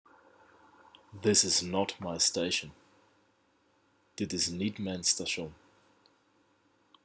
Pronounced like neat.
It sounds as though you have an extra syllable between dit _ niet ?
LMAO it’s like your DNA has a bass sounding chromosome.